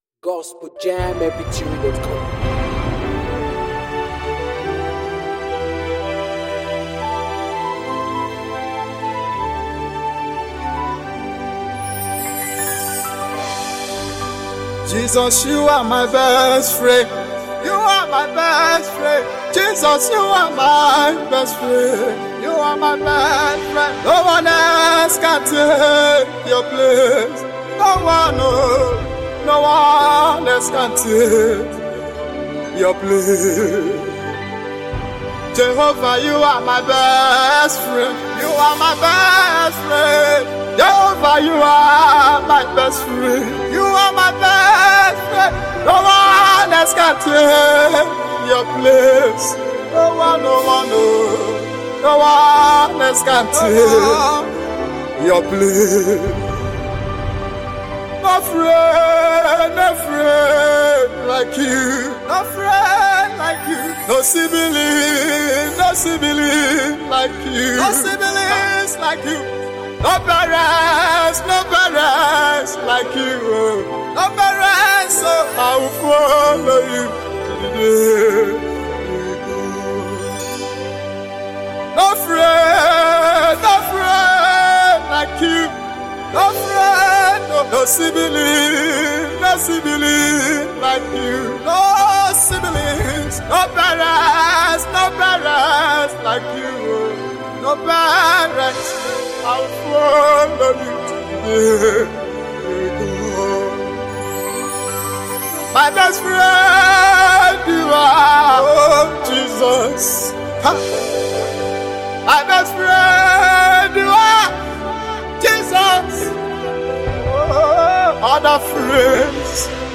powerful gospel song
With heartfelt lyrics and uplifting melodies